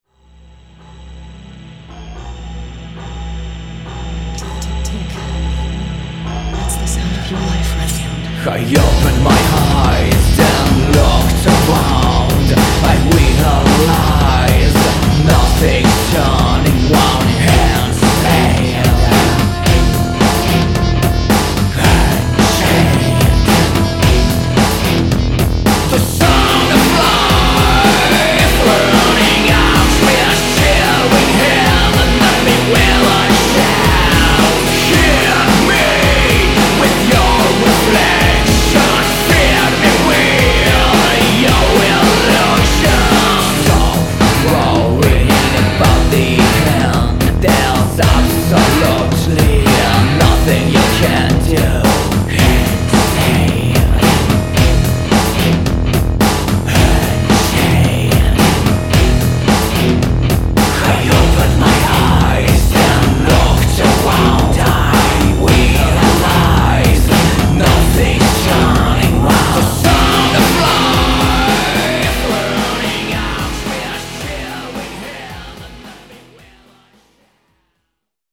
Industrial
And it sounds as hell !